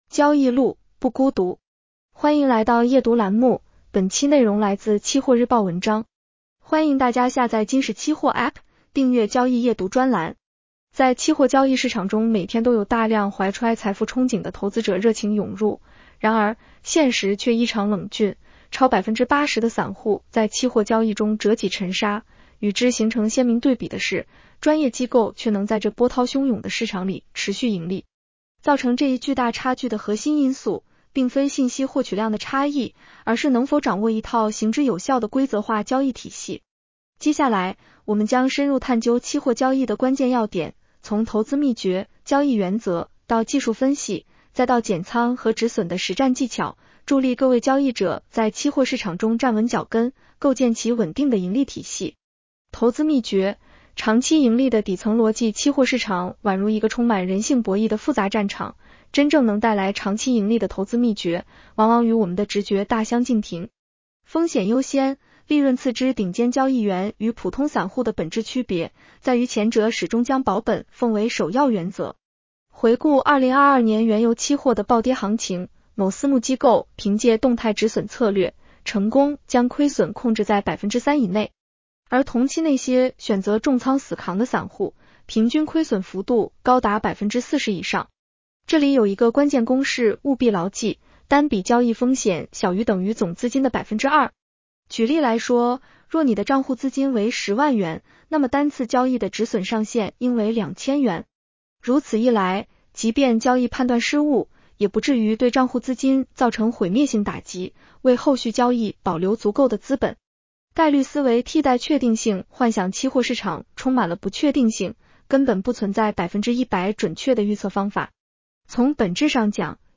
女声普通话版 下载mp3 在期货交易市场中每天都有大量怀揣财富憧憬的投资者热情涌入，然而，现实却异常冷峻，超80% 的散户在期货交易中折戟沉沙，与之形成鲜明对比的是，专业机构却能在这波涛汹涌的市场里持续盈利。